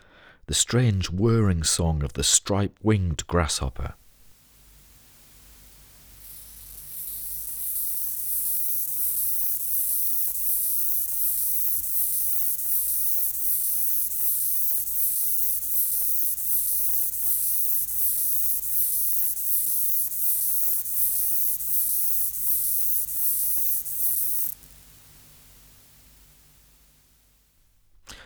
Species: Stenobothrus lineatus
Collins Field Guide - Stripe-winged Grasshopper.wav